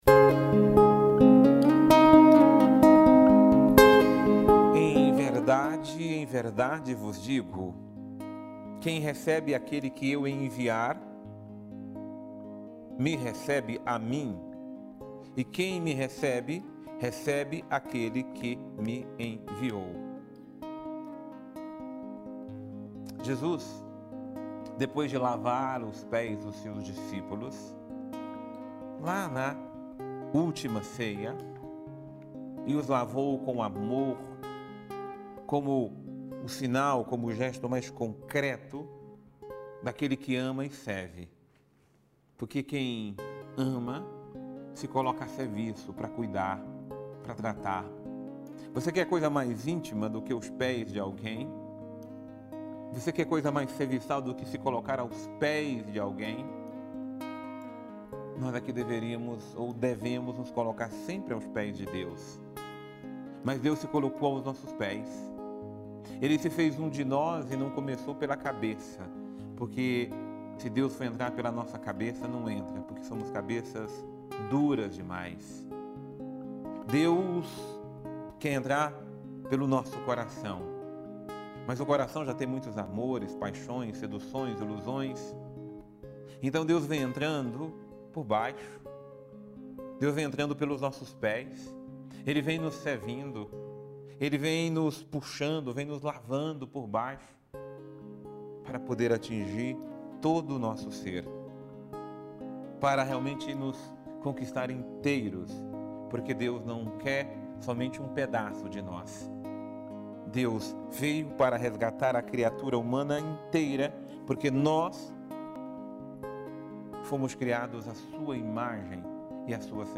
Homilia diária | Quem recebe Jesus, recebe também o Pai